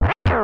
Sound effect from Super Mario RPG: Legend of the Seven Stars